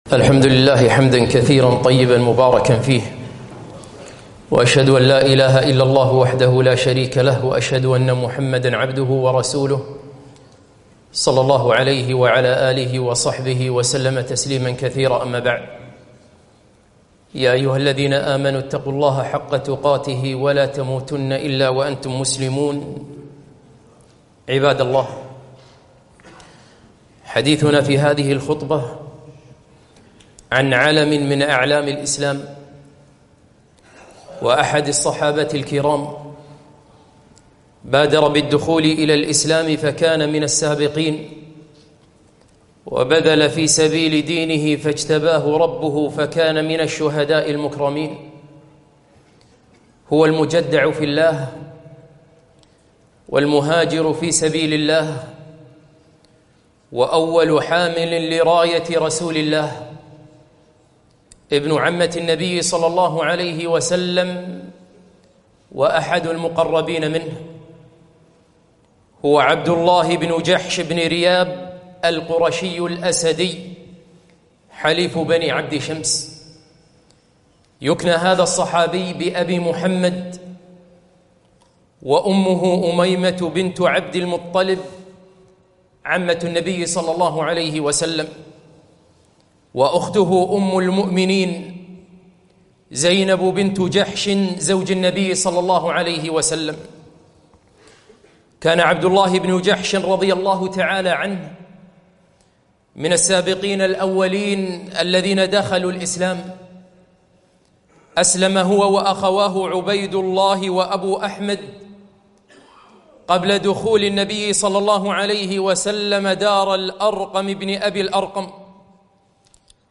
خطبة - المجدع في الله عبدالله بن جحش